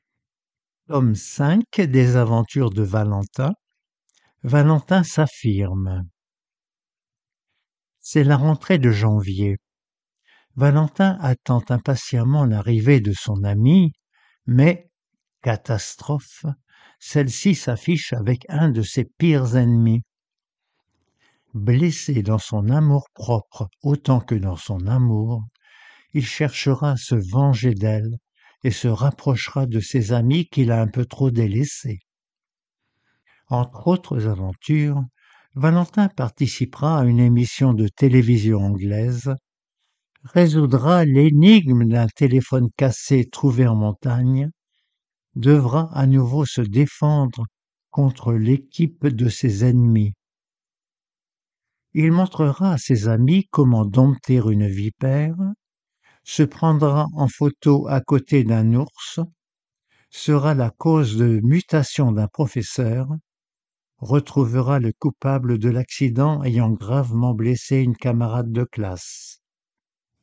Romans audios pour adultes mal-voyants